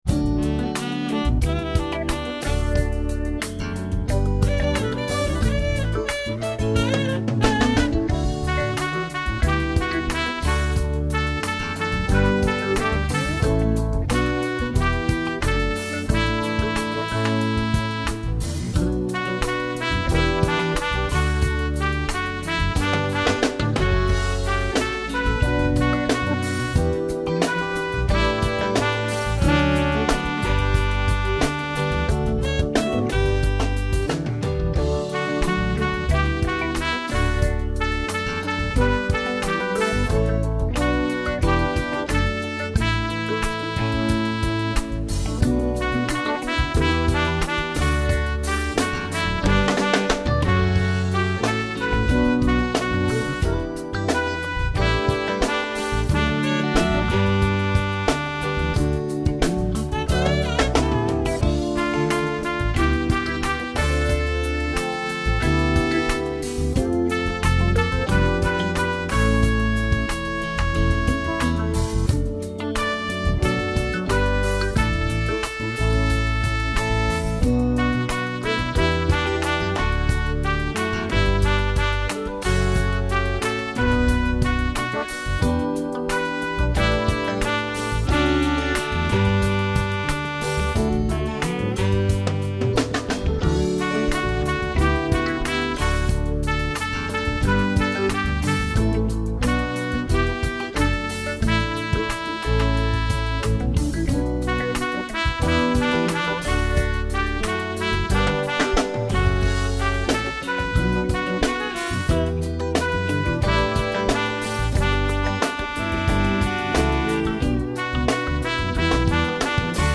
in a gentle funk style